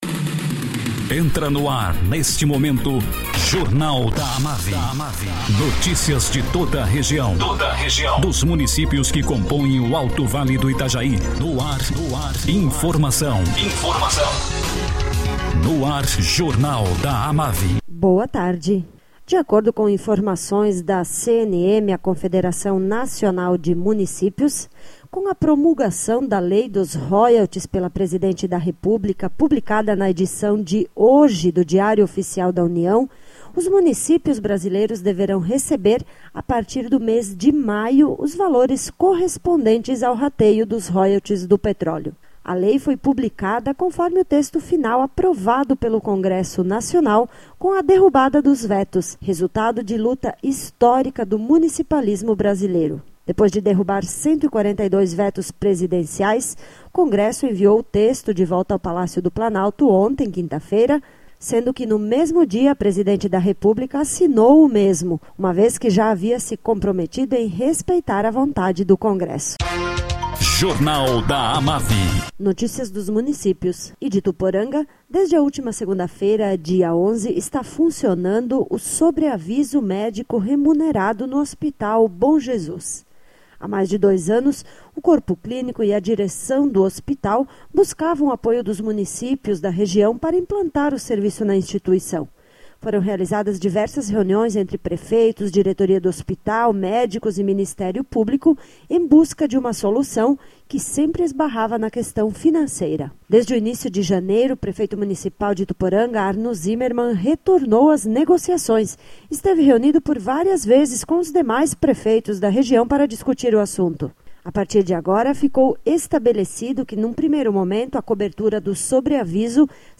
Presidente da AMAVI, prefeito Hugo Lembeck, fala sobre a reunião realizada na última sexta-feira, par adiscutir o traçado das Ferrovias a serem construídas em Santa Catarina.